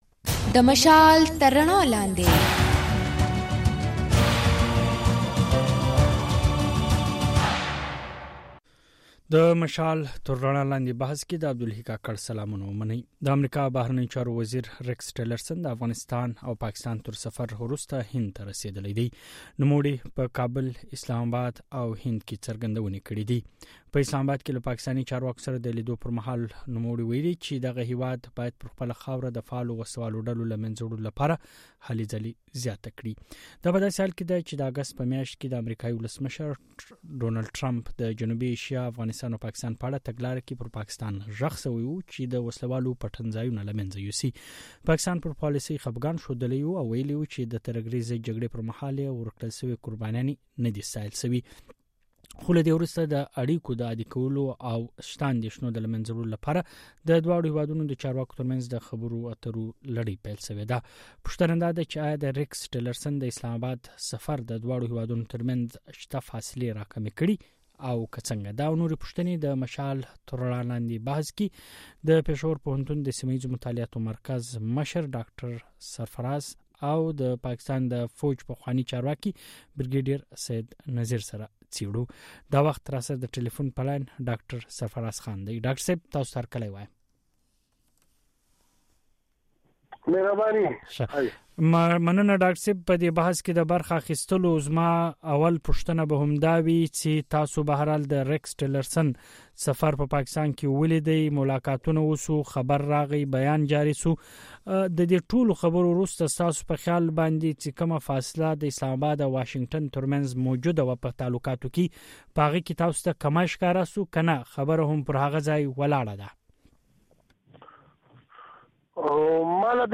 دا د مشال راډیو د ځانګړي بحث او شننو اوونیزې خپرونې پاڼه ده.